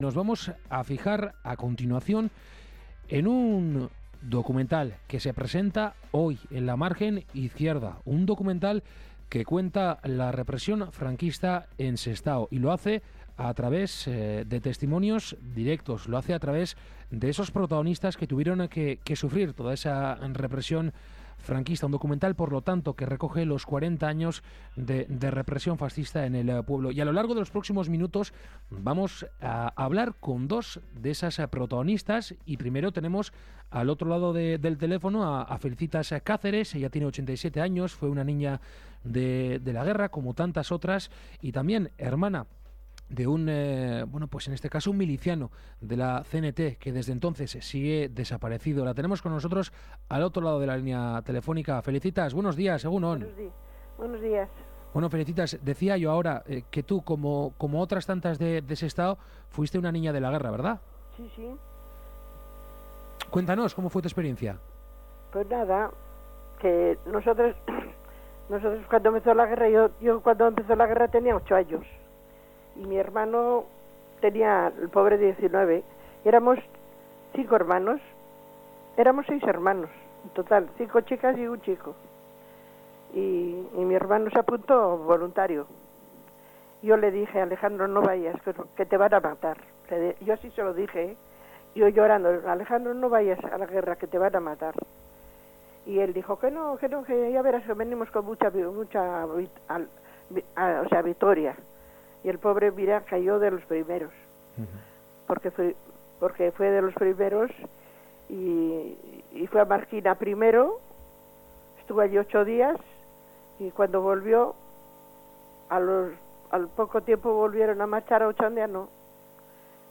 Entrevista con una víctima de la represión franquista en Sestao.